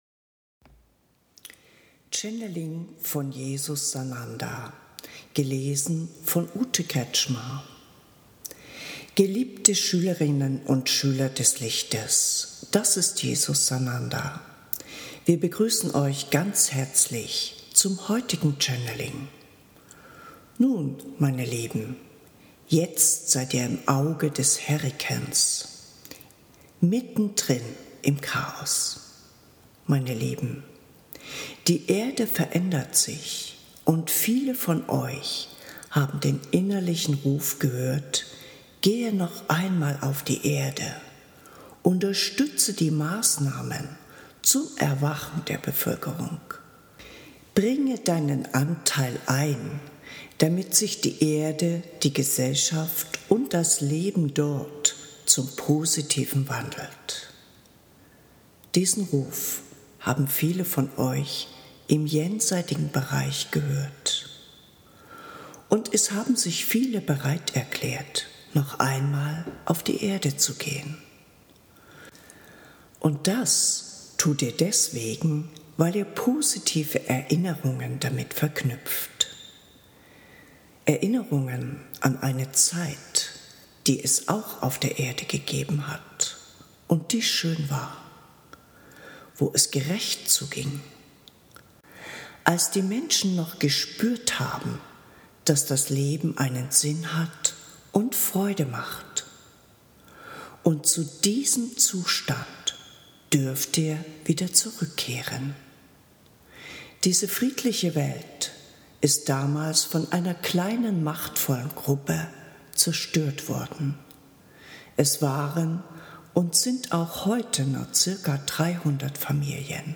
Hörbuch
Die Zeit des Wandels 5 4 Channelings gelesen & live